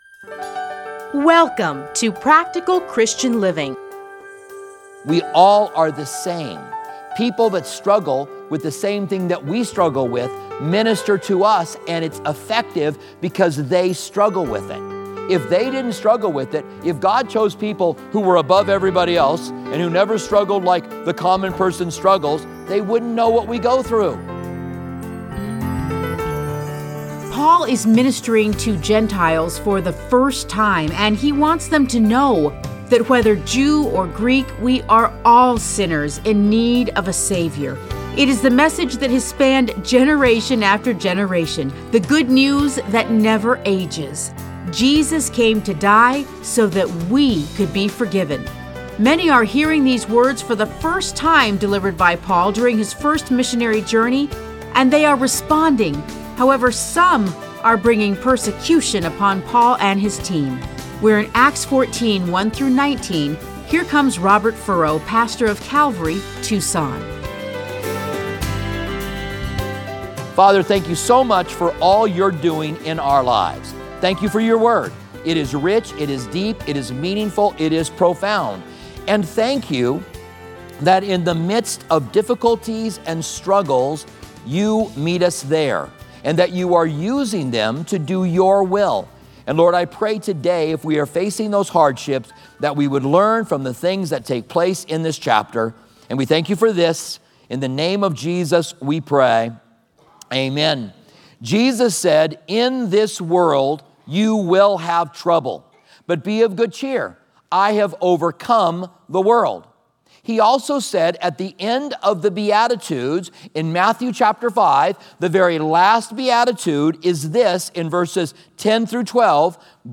Listen to a teaching from Acts 14:1-19.